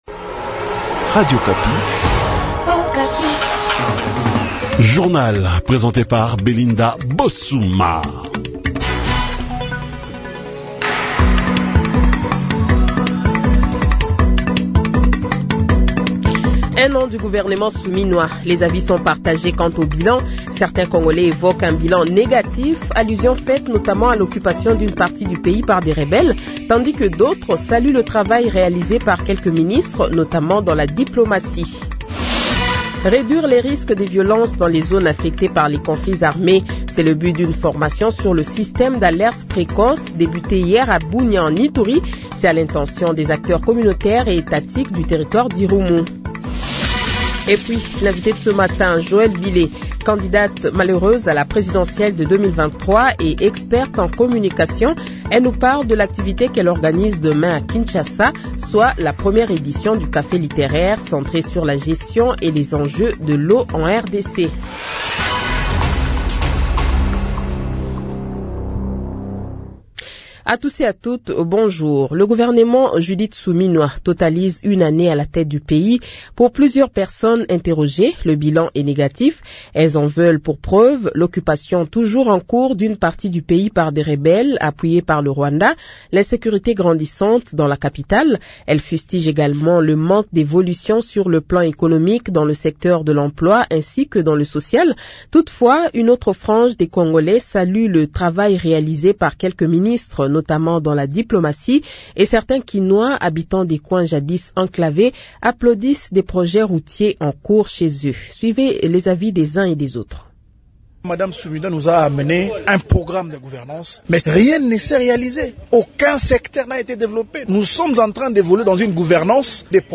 Le Journal de 8h, 13 Juin 2025 :